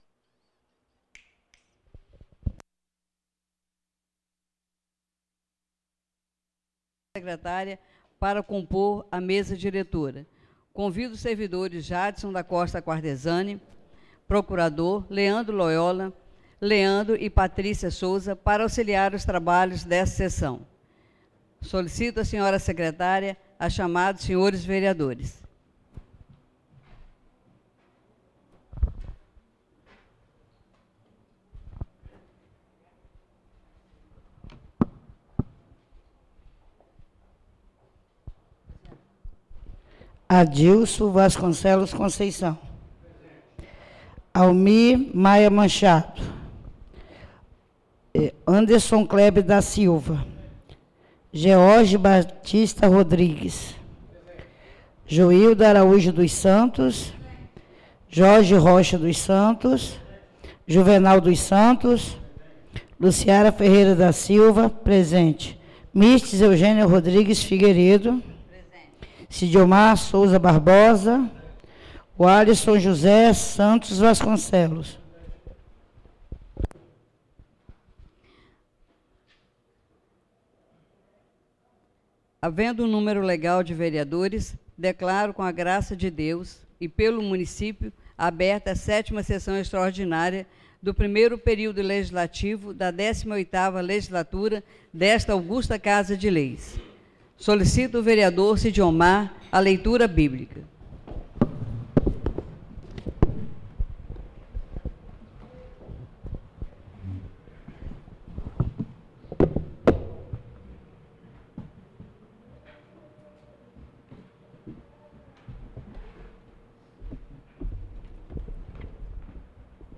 7ª (SÉTIMA) SESSÃO EXTRAORDINÁRIA PARA A DATA DE 18 DE OUTUBRO DE 2017.